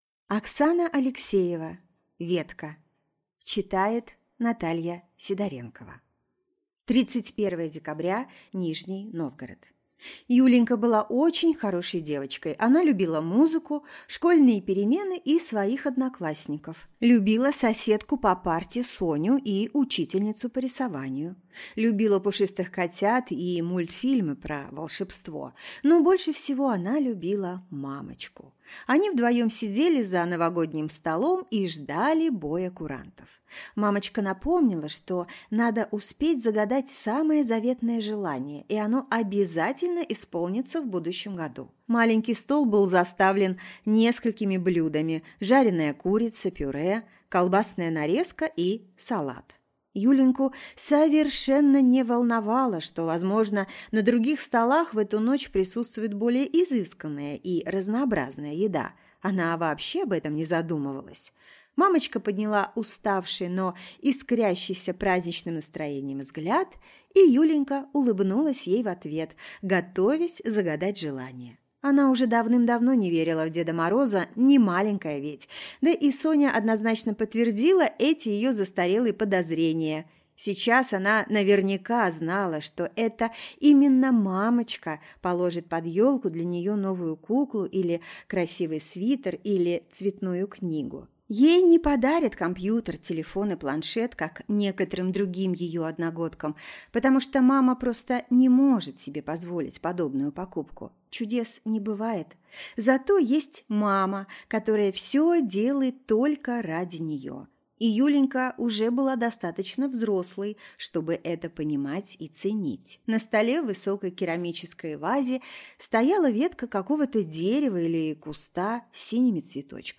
Аудиокнига Ветка | Библиотека аудиокниг